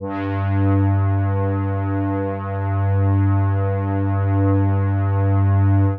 G2_trance_pad_1.wav